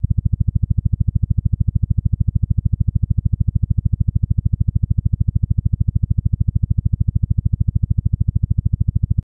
Helicopter Blade Whops Low Rhythmic